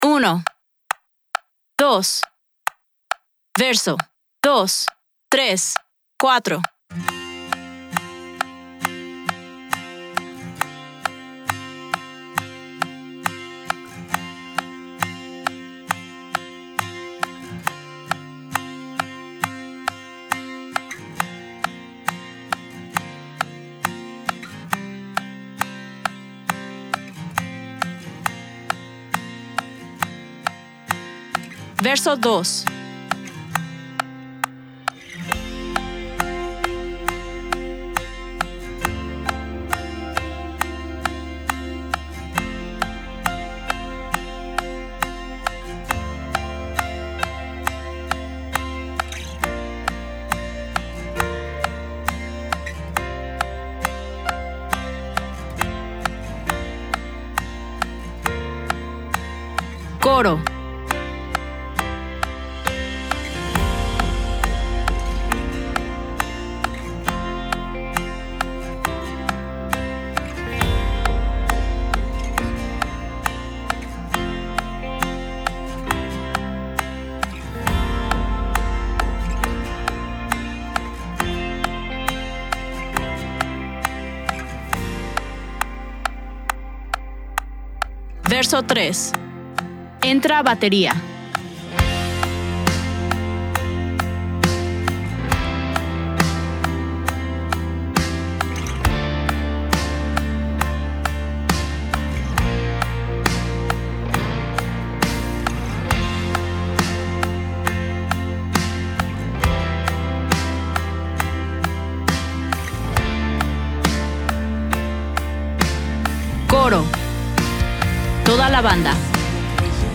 DEMO DEL MULTITRACKS